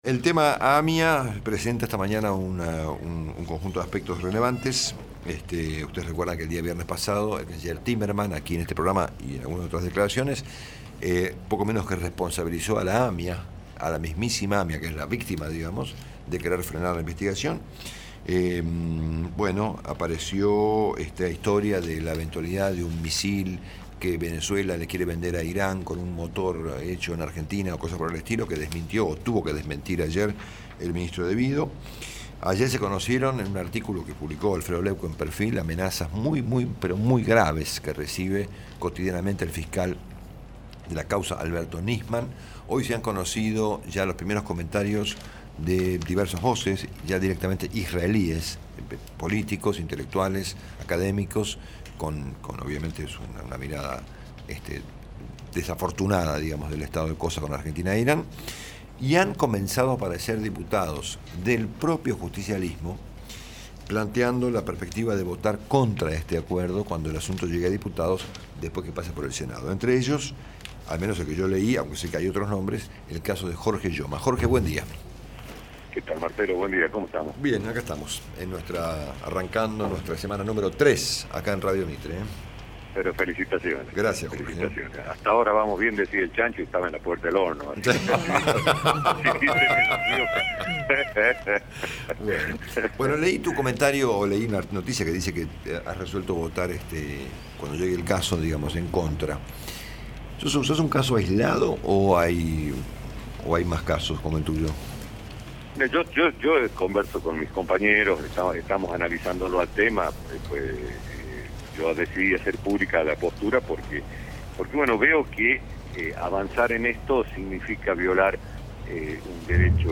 Jorge Yoma, diputado nacional, por Radio Mitre